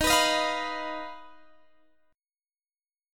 Ebsus2#5 Chord
Listen to Ebsus2#5 strummed